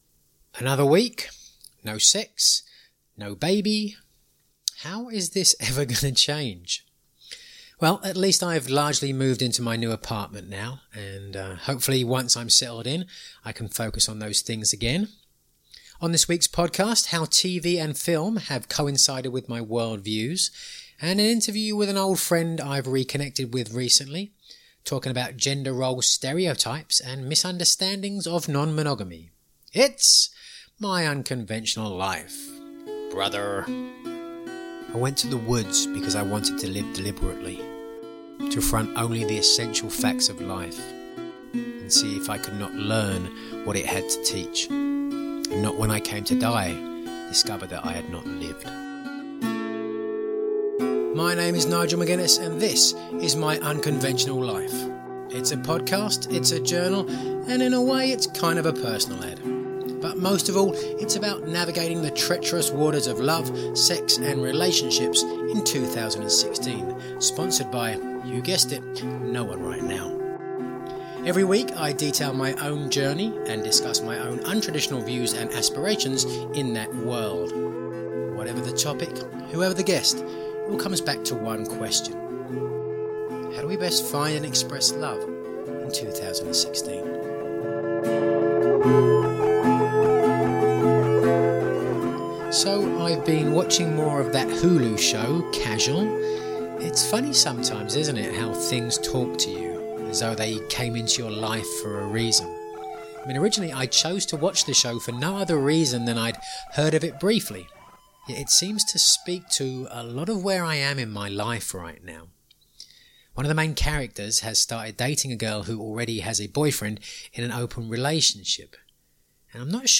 Monologue about how movies and TV are reflecting my worldviews.